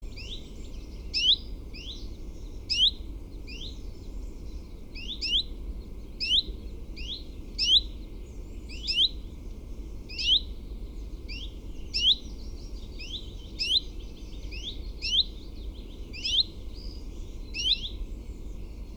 Sekalaulava tiltaltti / A song switching Common Chiffchaff (Phylloscopus collybita)
At a well-visited birdwatching site, Viikki in Helsinki, there has been a song switching Phylloscopus warbler for some time.
Molemmat linnut ääntelivät ahkerasti.
This recording includes both calling simultaneously.